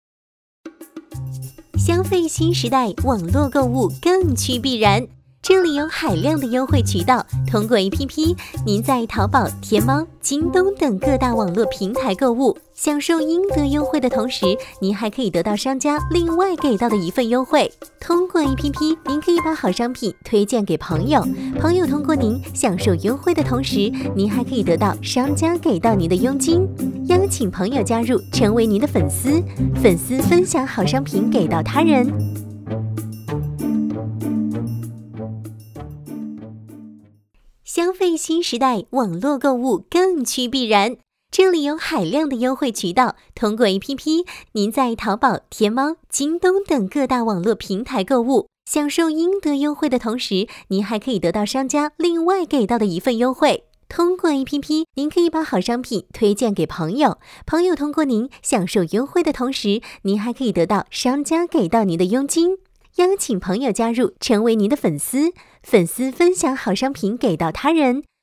女国48_广告_促销_商场清仓促销_激情-新声库配音网
配音风格： 激情 大气 亲切 甜美 年轻
女国133_宣传片_病毒_飞碟说诙谐甜美网络购物.mp3